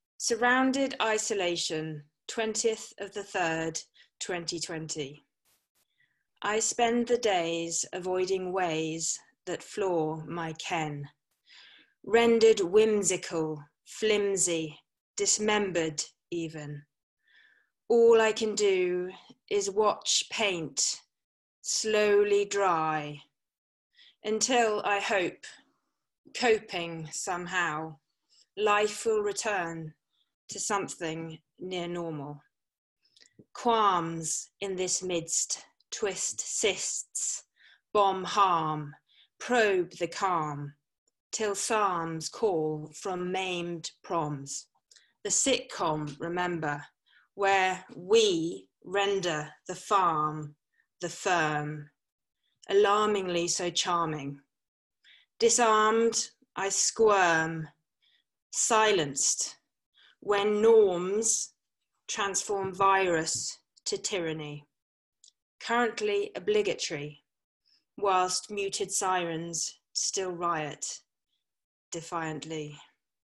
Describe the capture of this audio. She wrote this poem about being isolated in the context of the Coronavirus and where she lives in a farm in the middle of nowhere. Here is the recording of her performance of it at our online jam themed around “isolation”.